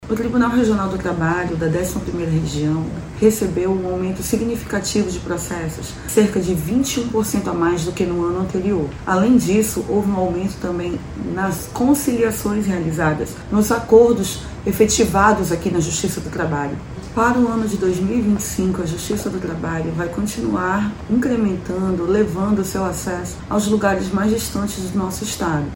Este indicador é importante para entender o andamento e a resolução dos casos no tribunal, seja pela execução, segunda instância ou arquivamento definitivo, como explica a juíza do trabalho, Carla Nobre.